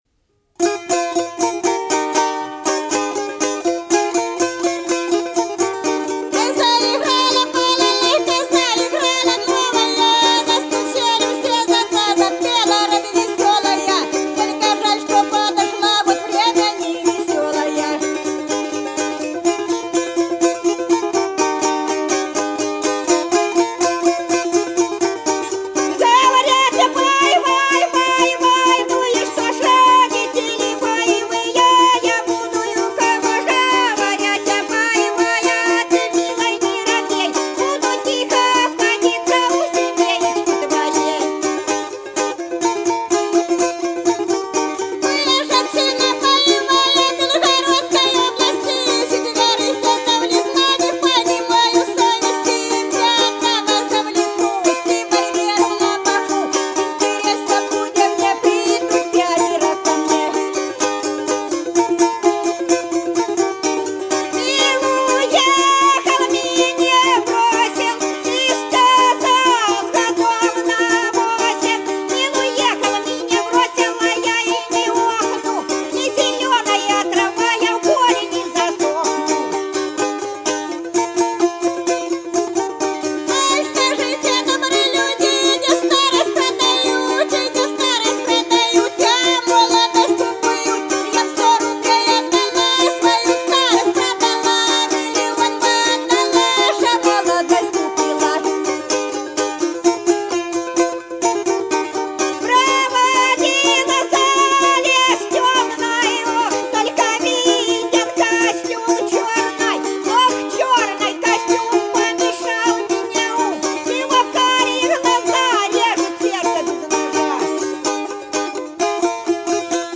По-над садом, садом дорожка лежала Заиграла балалайка - частушки под балалайку (с.Фощеватово, Белгородская область)
32_Заиграла_балалайка_(частушки_под_балалайку).mp3